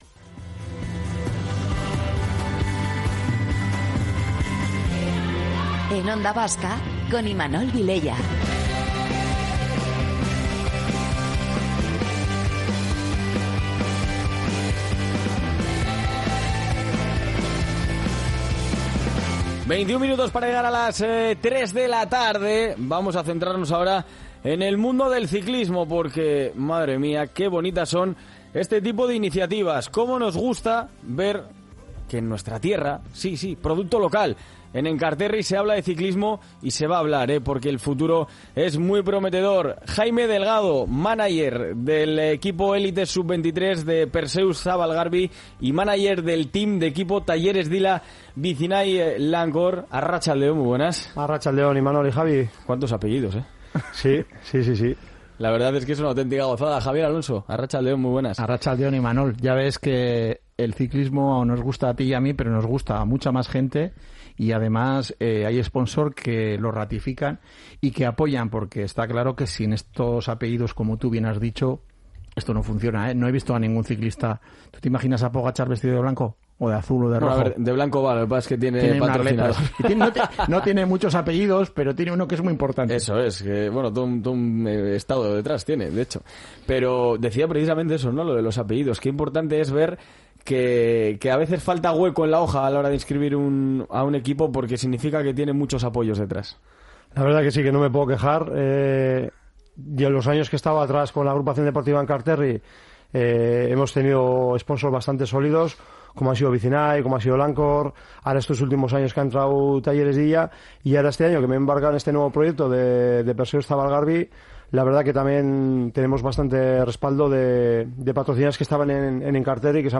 Entrevistas deportivas